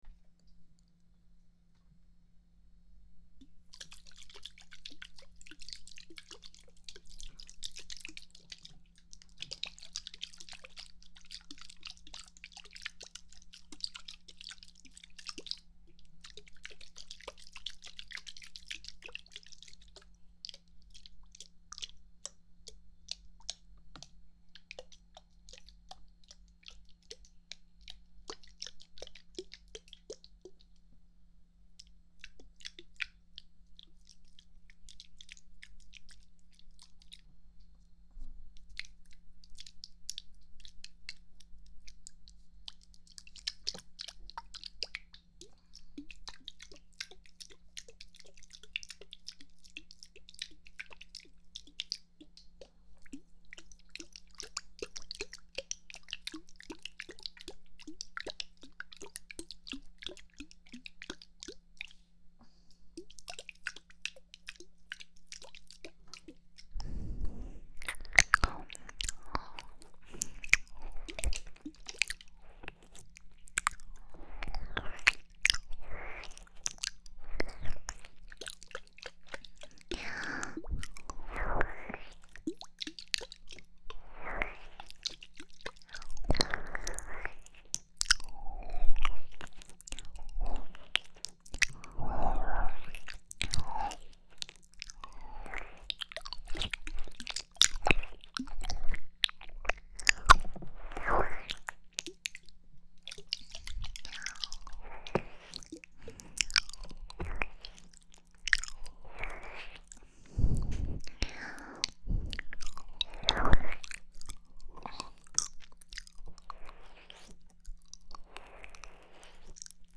ASMR在线